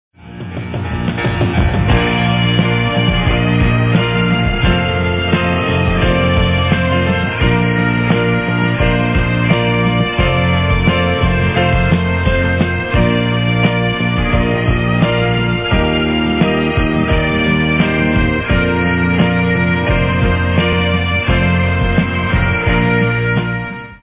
pipers from Churchill School perform the national anthem at the Rhodesia Light Infantry Cranborne barracks